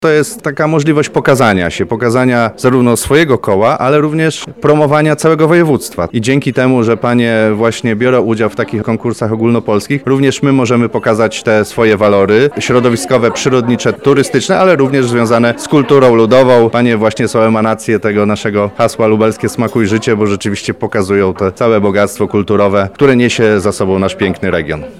– mówi Marek Wojciechowski, Wicemarszałek Województwa Lubelskiego.